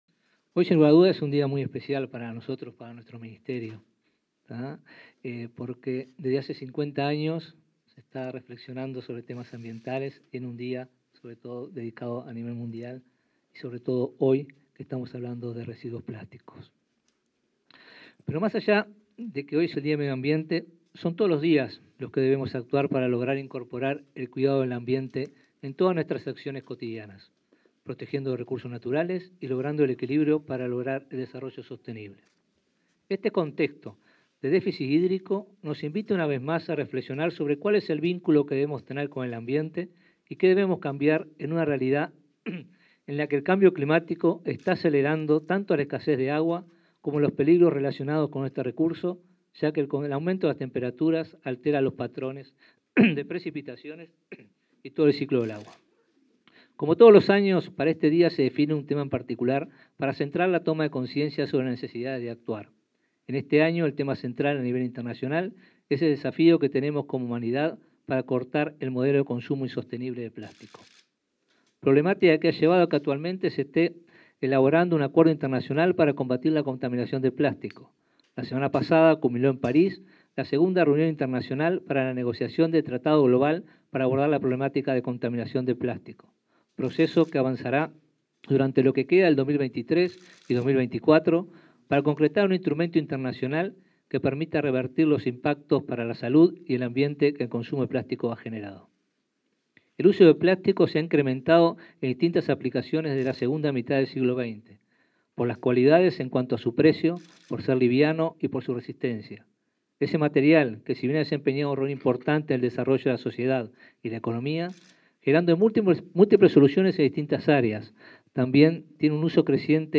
Palabras del ministro de Ambiente, Robert Bouvier